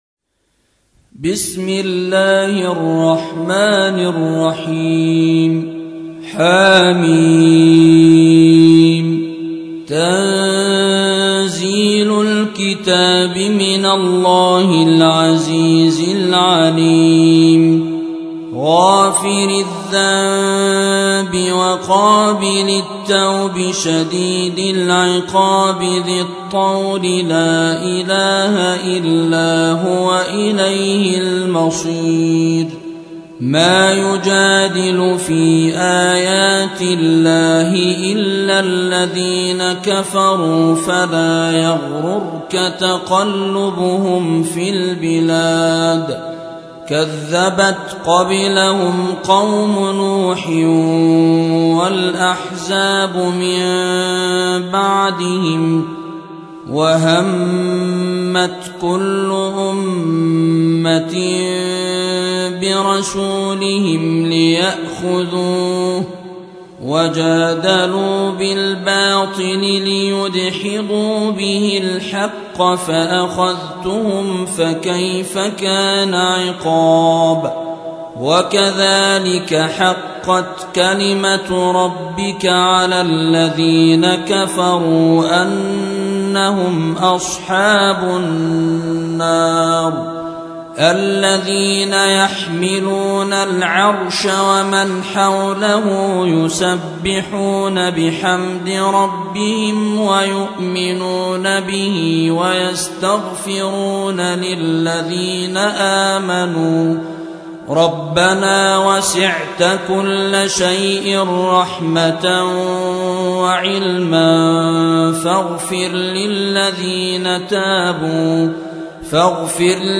40. سورة غافر / القارئ